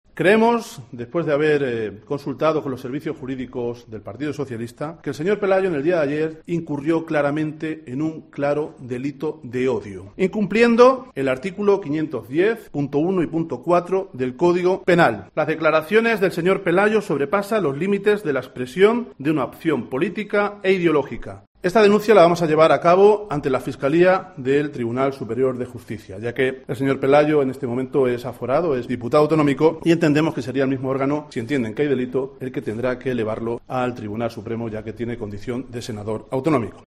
Así lo ha anunciado el secretario general del PSOE de la provincia de Badajoz, Rafael Lemus, durante una rueda de prensa en la que ha tildado dichas declaraciones, en las que Gordillo dijo que "espera que los inmigrantes acogidos no alteren la convivencia y la seguridad en la ciudad", de "bochornosas y peligrosas".